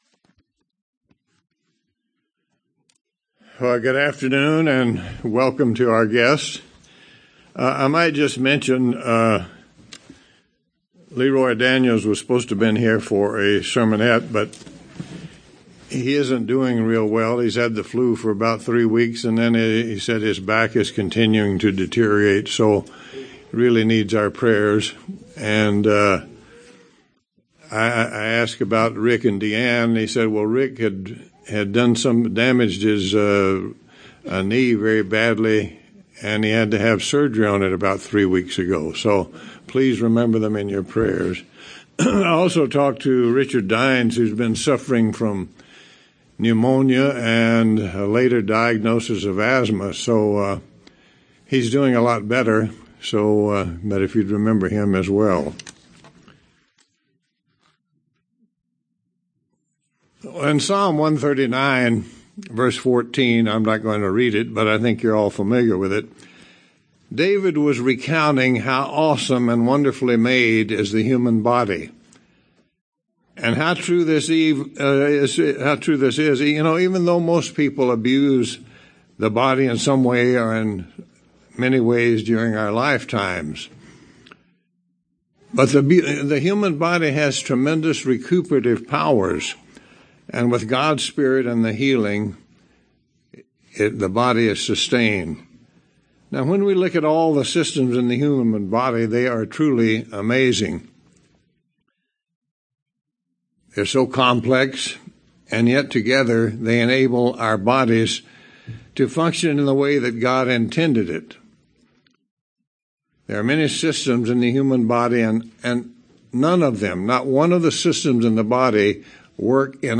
Given in Tucson, AZ El Paso, TX
UCG Sermon Studying the bible?